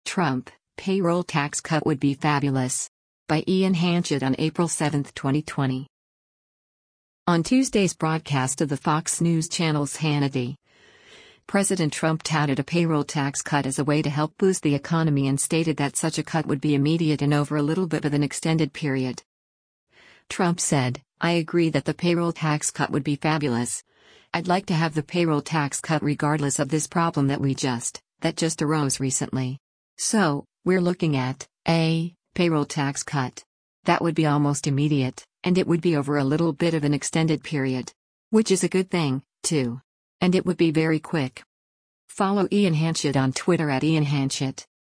On Tuesday’s broadcast of the Fox News Channel’s “Hannity,” President Trump touted a payroll tax cut as a way to help boost the economy and stated that such a cut would be “immediate” and “over a little bit of an extended period.”